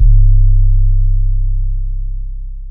YM 808 7.wav